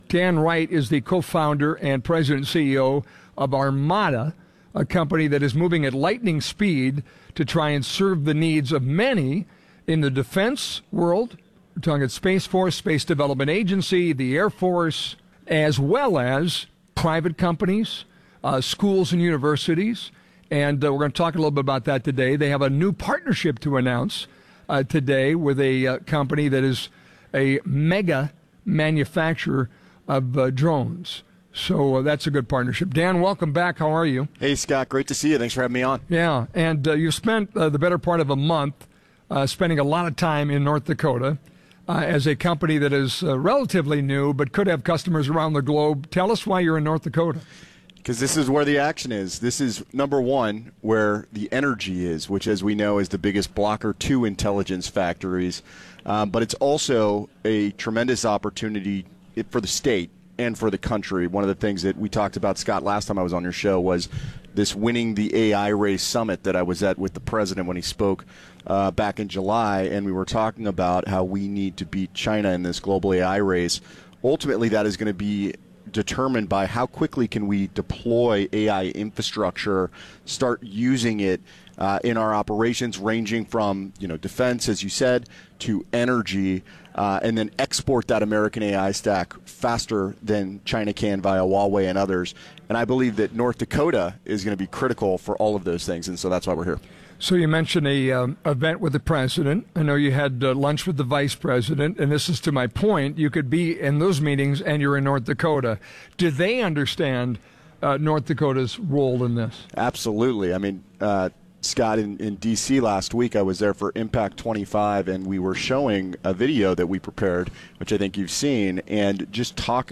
The annual two-day UAS Summit & Expo took place at the Alerus Center in Grand Forks on October 14th & 15th.